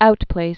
(outplās)